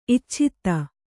♪ icchitta